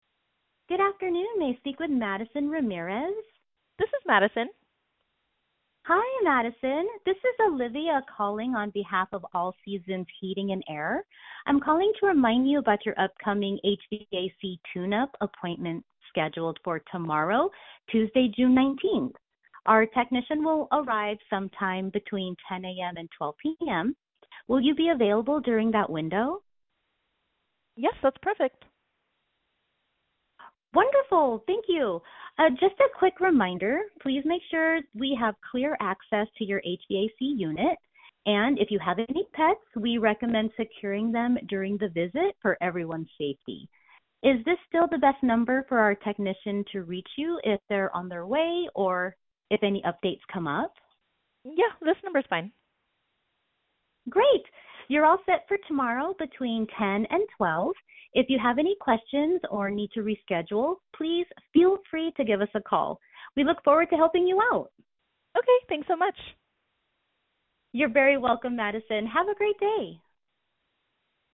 Service Reminder Call
HUMAN RECEPTIONIST
Service-Reminder-Call-Human.mp3